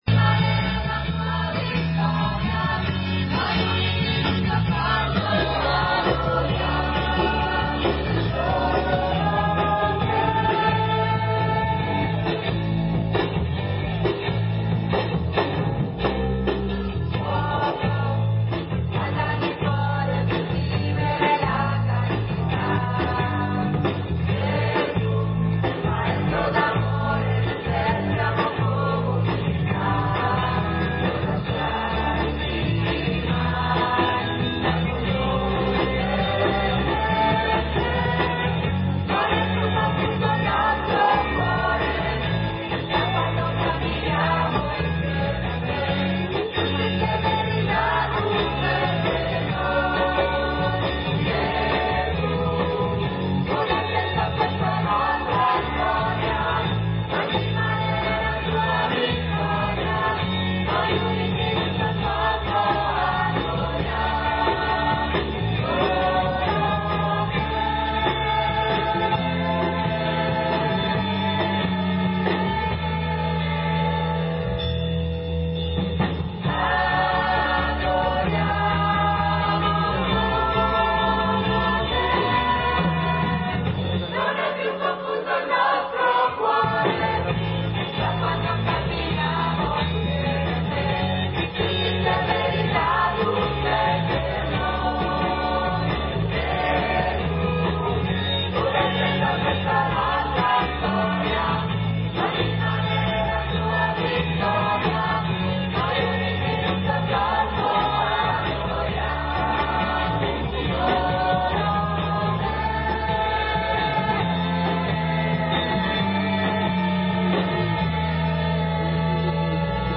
All'aperto. Canzoni e preghiere. Applausi e flash.
Batteria, sax e chitarre elettriche nella parrocchia dedicata a Santa Maria Goretti.
Ieri sera alle 21 è cominciata la messa rock nella parrocchia di San Mauro Mare, ed è andata avanti per un’ora e mezza.
messa-rock-1.mp3